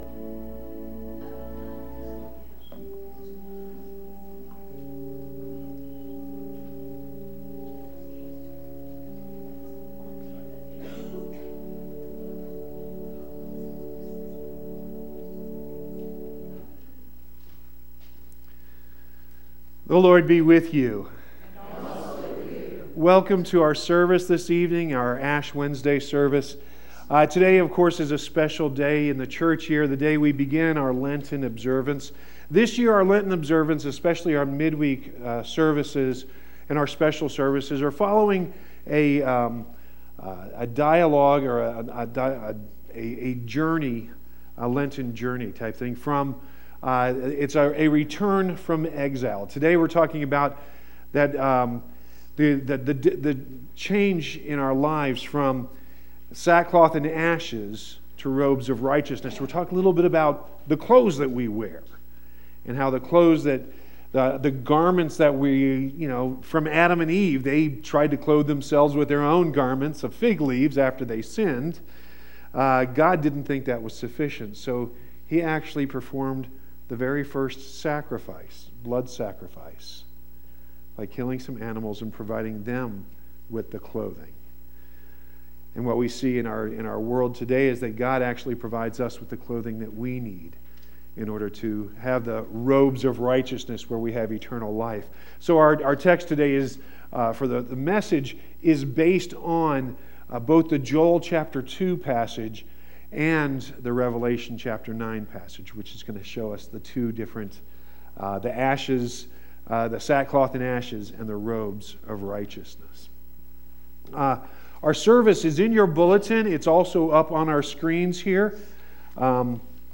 Special treat tonight, click below to hear the whole Ash Wednesday service. If you just want the sermon it starts at 47 minutes. The choir can be heard at 32 minutes.